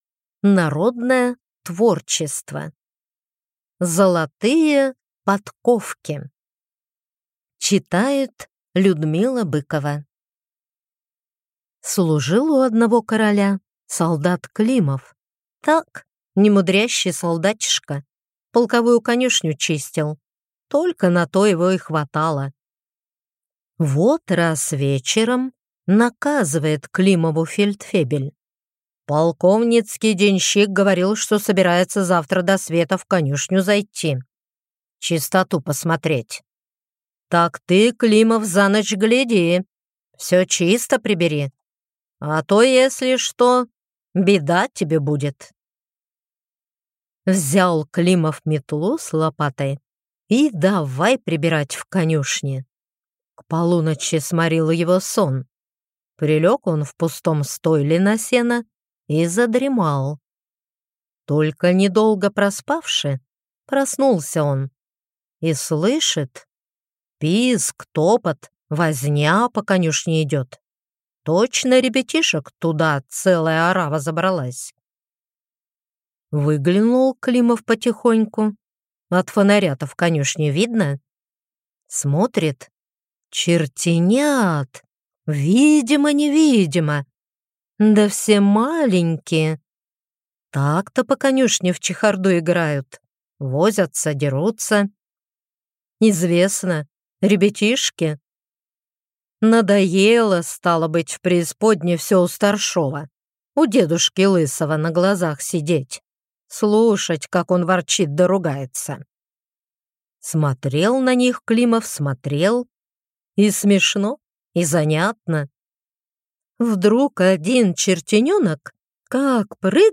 Аудиокнига Золотые подковки | Библиотека аудиокниг